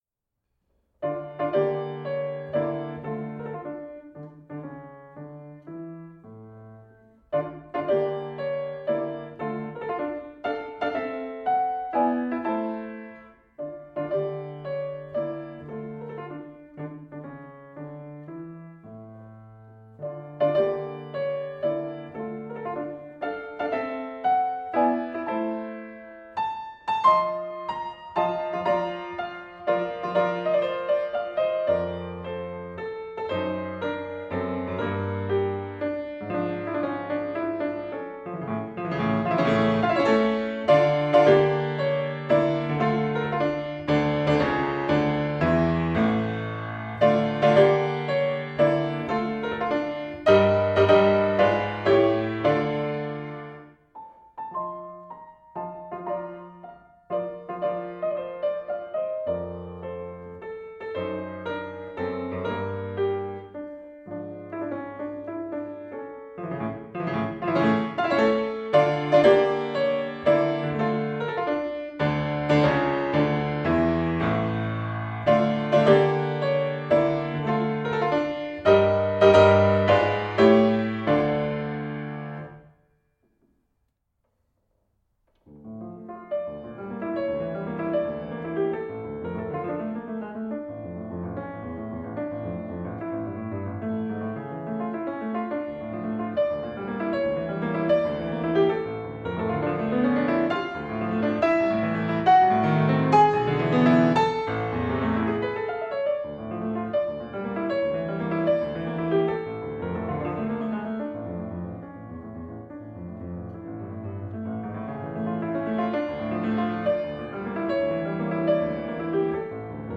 self-recorded